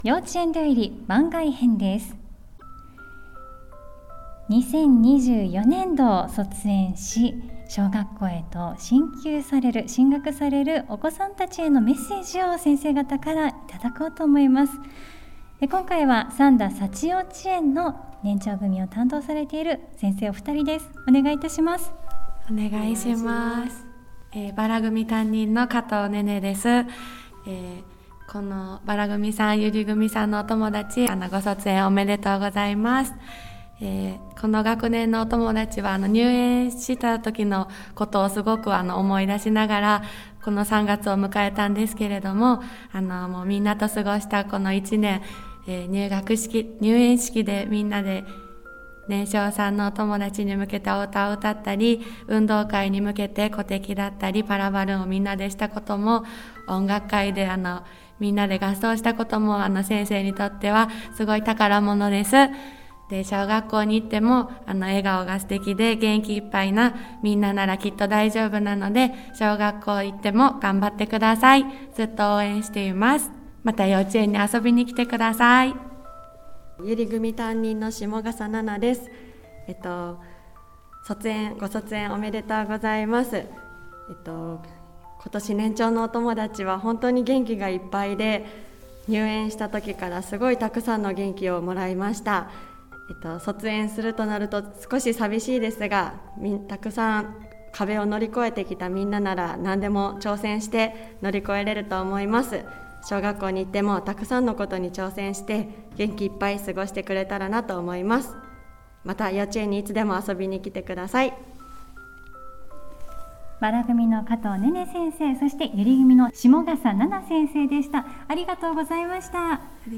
幼稚園だより特別編！この春卒園、進学する年長さんたちに向けて、担任の先生方からメッセージをいただきました🌸